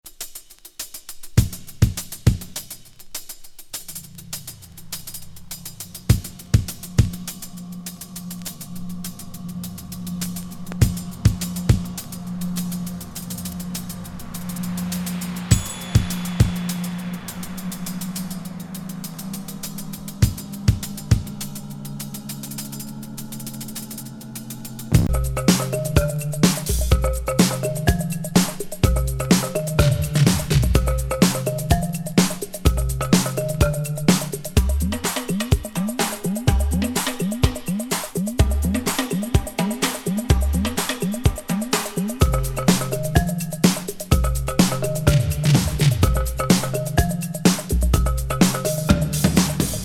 エフェクタブル奇天烈ドラム
ミュータント感漂う